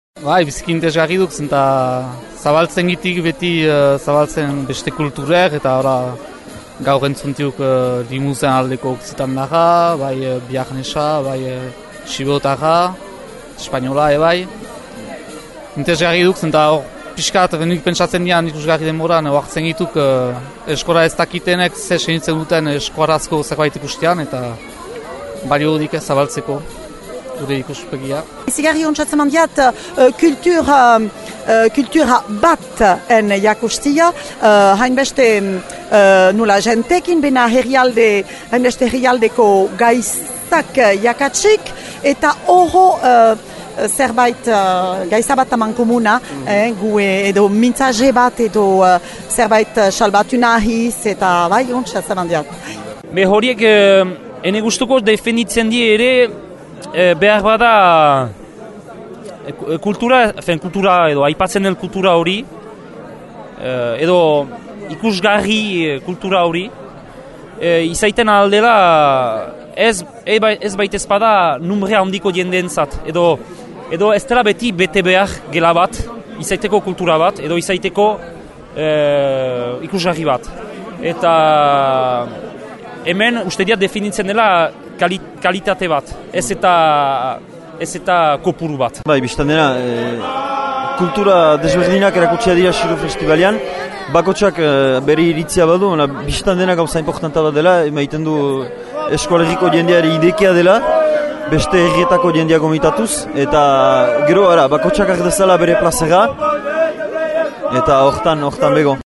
Entzün so egileen erranak neskenegün gaüan :